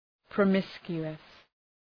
Προφορά
{prə’mıskju:əs}